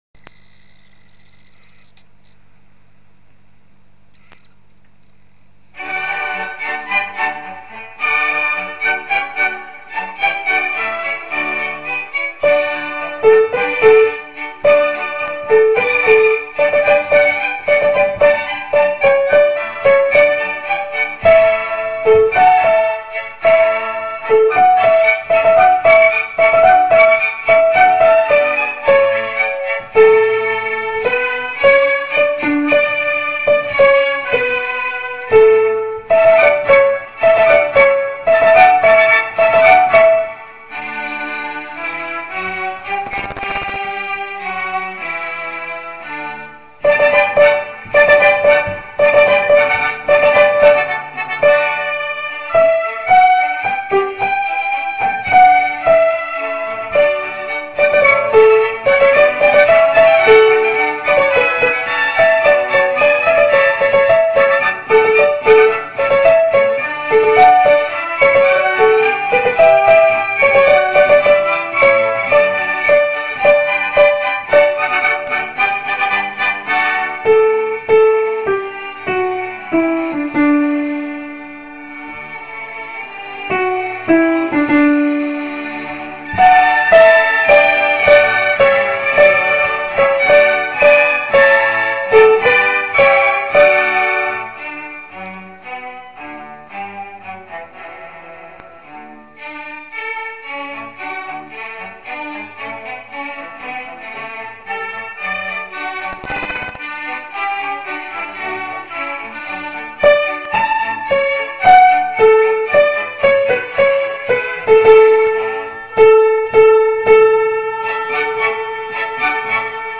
诗班献诗的部分诗歌已经选定，并将陆续更新，请大家自行下载练习（请点击诗歌链接）。
女中音
5 哈利路亚 Hallelujah Chorus soprano.mp3